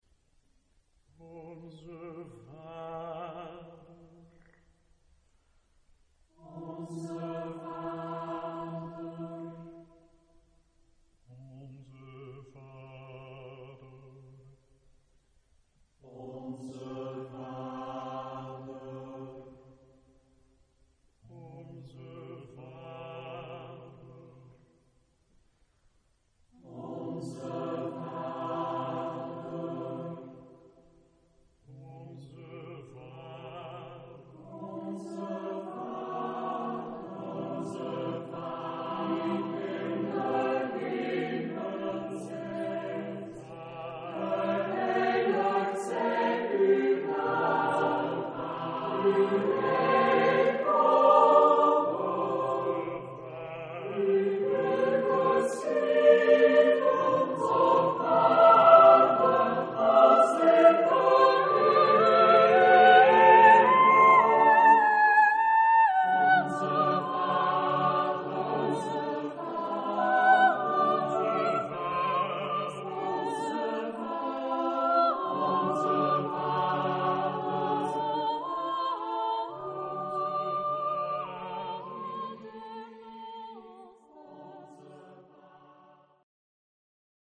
Genre-Style-Form: Sacred ; Prayer
Mood of the piece: meditative ; prayerful
Type of Choir: SSAATTBB  (8 double choir voices )
Soloist(s): Soprano (1)  (1 soloist(s))
Tonality: overtones